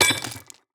UI_BronzeKick_Roll_02.ogg